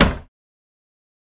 Cellar Door Quick Close